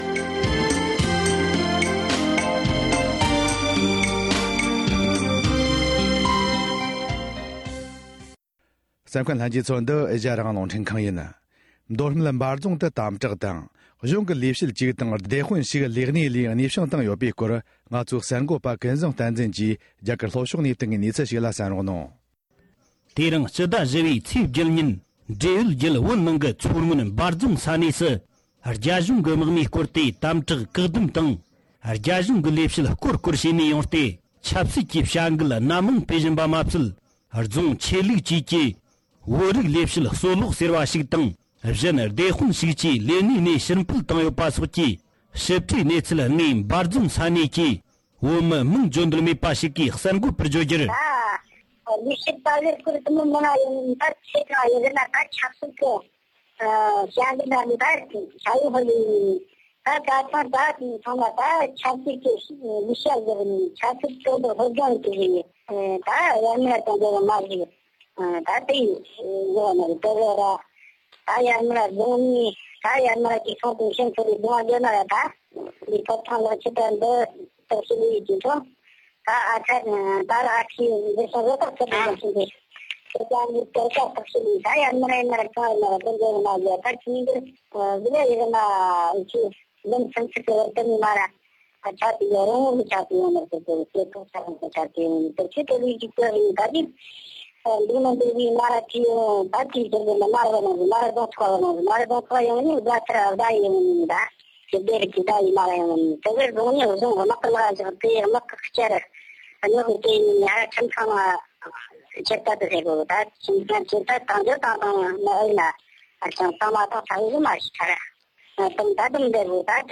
སྒྲ་ལྡན་གསར་འགྱུར། སྒྲ་ཕབ་ལེན།
འབྲེལ་ཡོད་མི་སྣ་ཞིག་གིས་འདི་གའི་གསར་འགོད་པ་